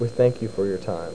Amiga 8-bit Sampled Voice
ThankYou.mp3